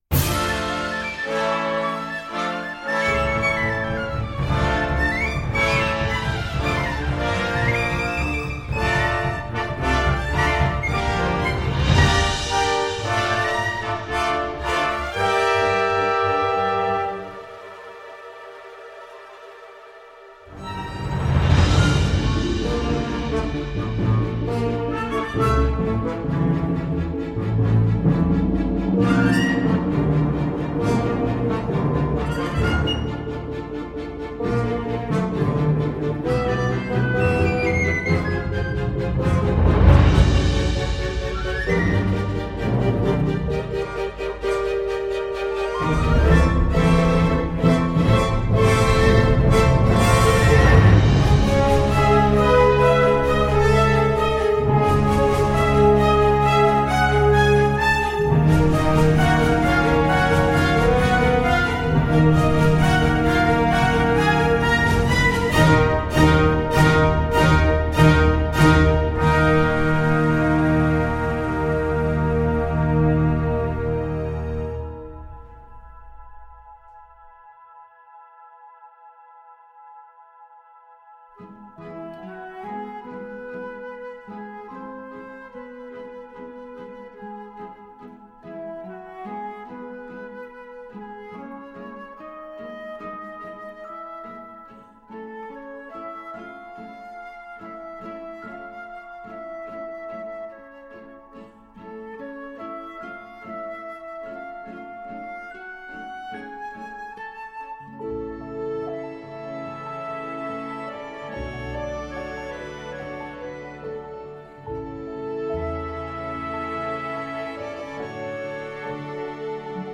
Hautement mélodique et souvent joyeux et ludique